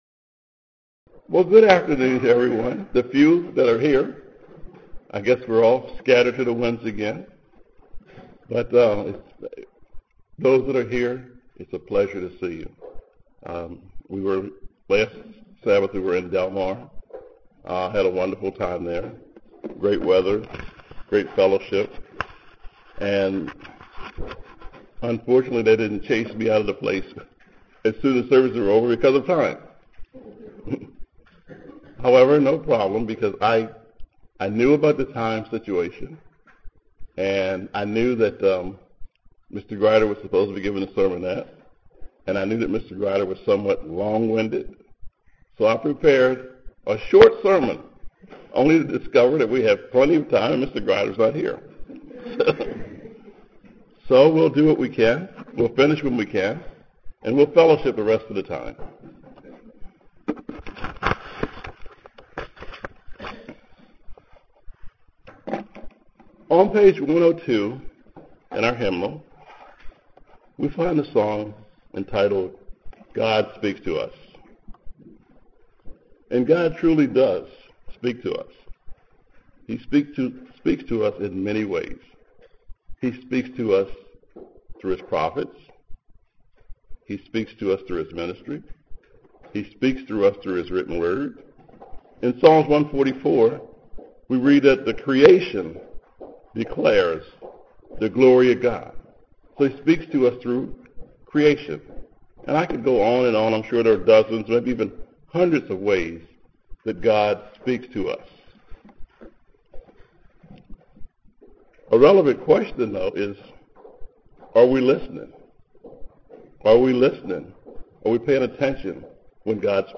UCG Sermon Studying the bible?
Given in Columbia, MD